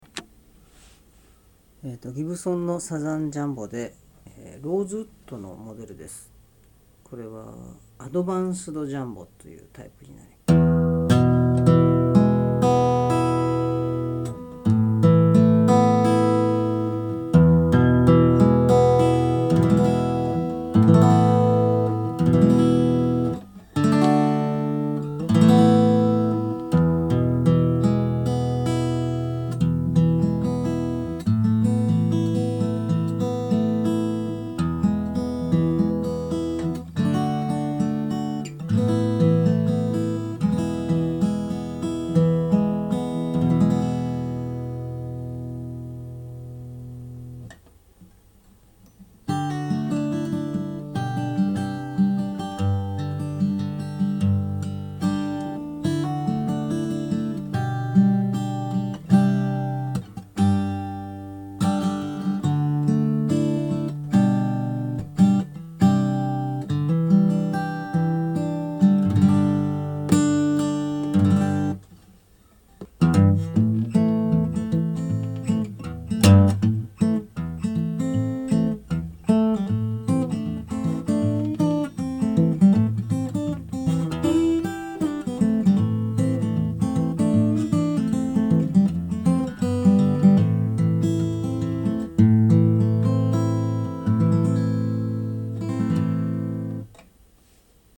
ニコアースをギブソンのアドバンスドジャンボに付けました。 ギブソンサウンドをラインで出せるでしょうか。